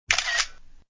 iphonecam.mp3